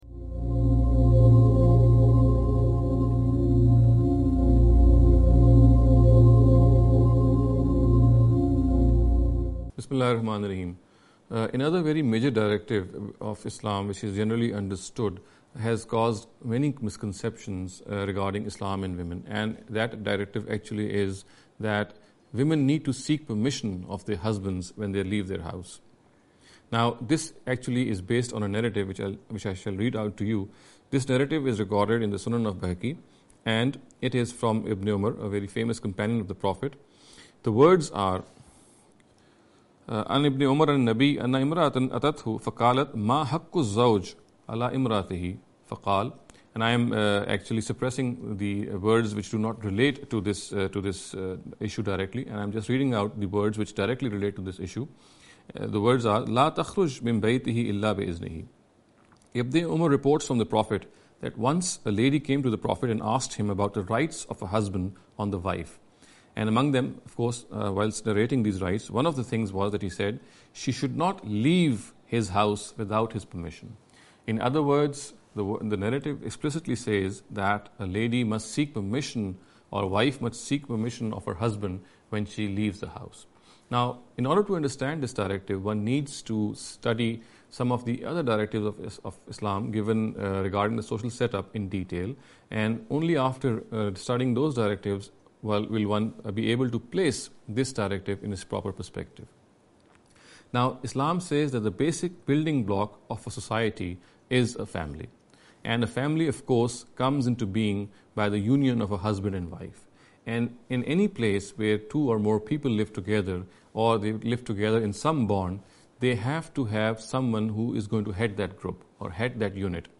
In every lecture he will be dealing with a question in a short and very concise manner. This sitting is an attempt to deal with the question 'Husband’s Permission for Leaving the House’.